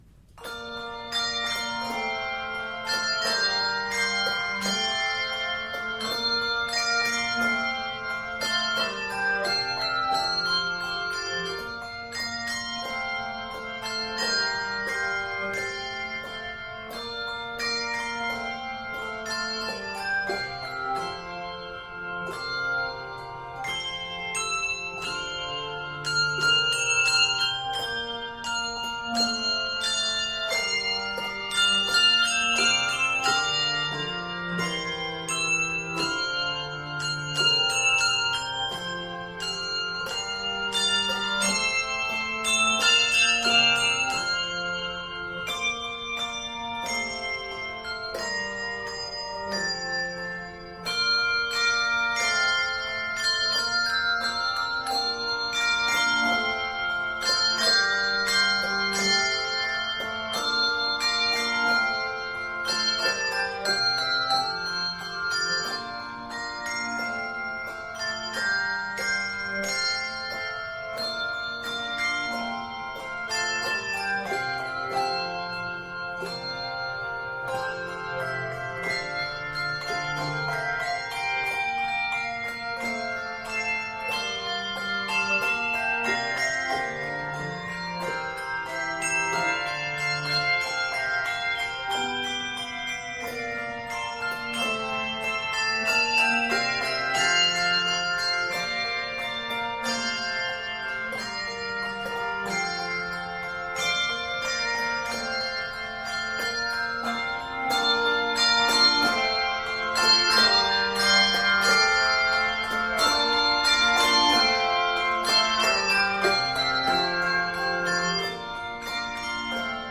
given a light, contemporary treatment
Octaves: 3-5